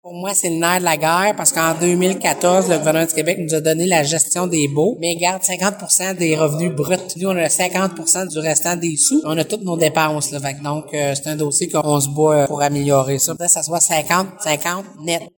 Les baux de villégiature rapportent 60 000 $ au budget des TNO en 2022, soit le même montant que l’an dernier. La préfète de la MRC Vallée-de-la-Gatineau, Chantal Lamarche, précise qu’elle souhaite que ce taux change et elle compte continuer les démarches pour améliorer cette situation :